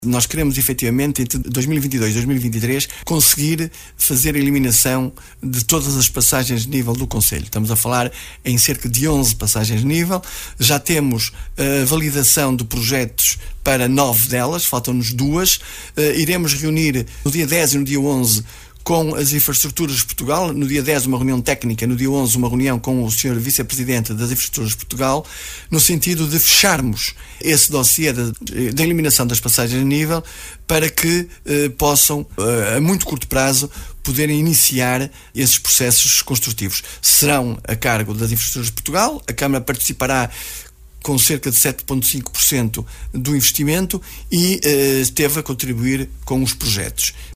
Uma prioridade anunciada pelo executivo presidido por Mário Constantino que espera acabar com passagens de nível entre 2022 e 2023, como referiu há dias em entrevista à Rádio Barcelos: